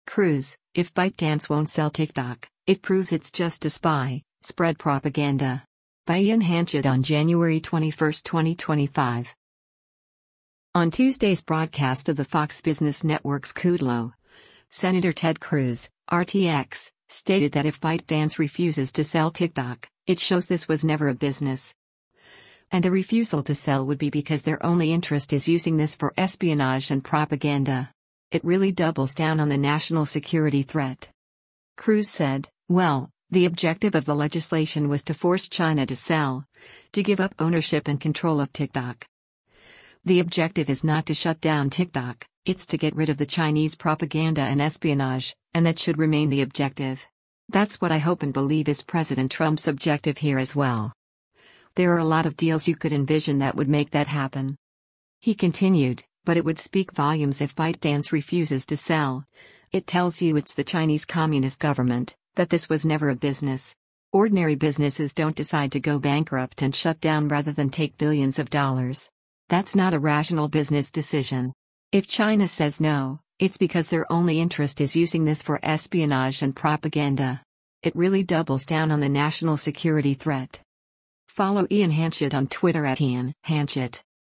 On Tuesday’s broadcast of the Fox Business Network’s “Kudlow,” Sen. Ted Cruz (R-TX) stated that if ByteDance refuses to sell TikTok, it shows “this was never a business.”